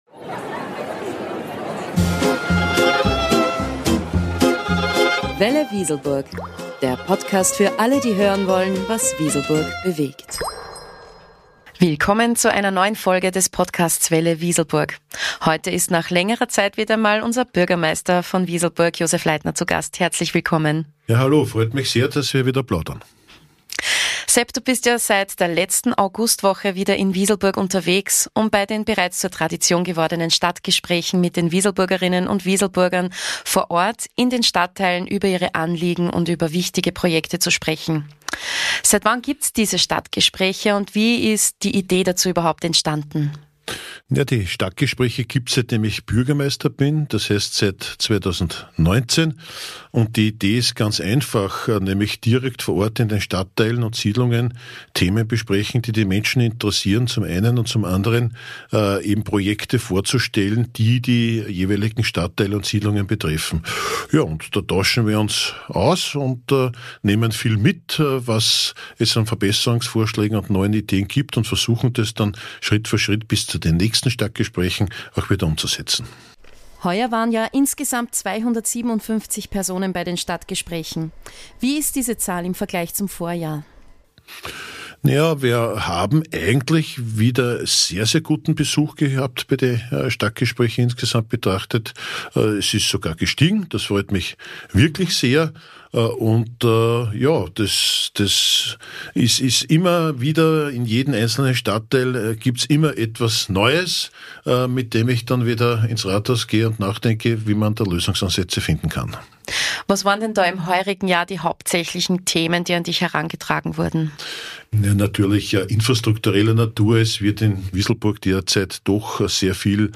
Folge 29 | Stadtgespräche mit Bürgermeister Josef Leitner ~ Welle.Wieselburg Podcast